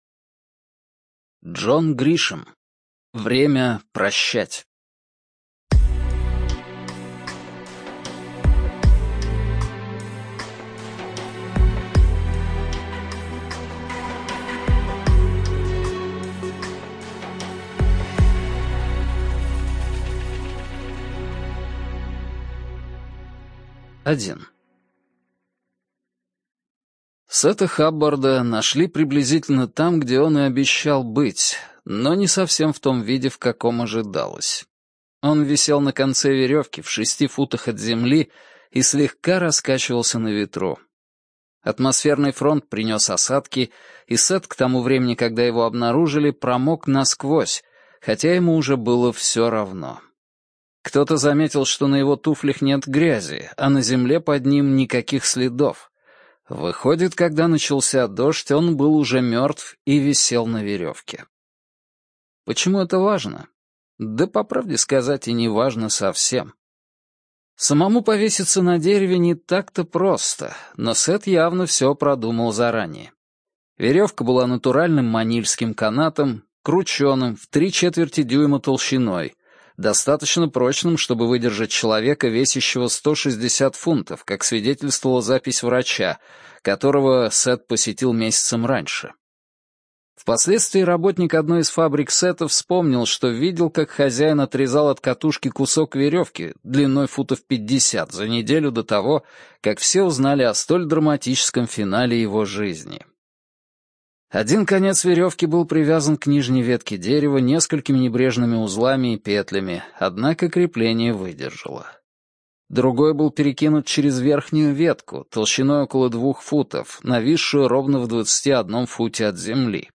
ЖанрДетективы и триллеры